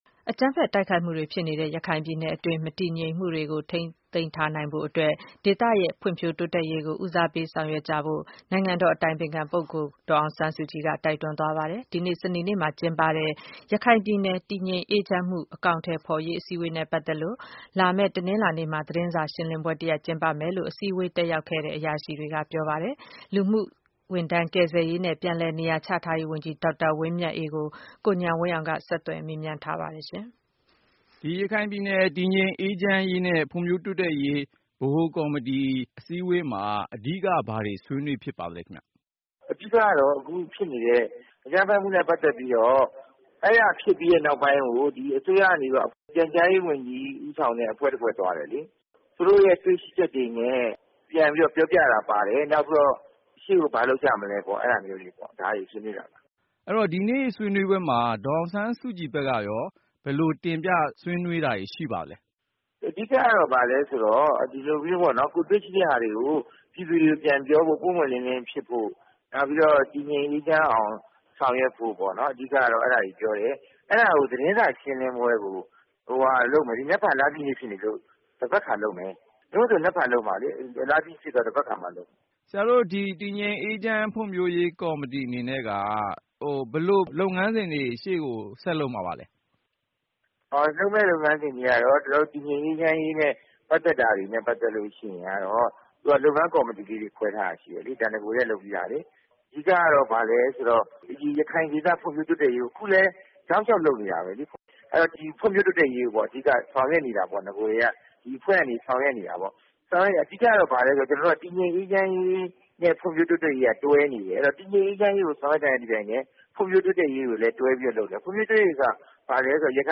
မေးမြန်း ရာမှာ လူမှုကယ်ဆယ်ရေးနဲ့ ပြည်လည်နေရာ ချထားရေးဝန်ကြီး ဒေါက်တာ ဝင်းမြတ်အေး က ခုလို စတင်ဖြေကြားပါတယ်။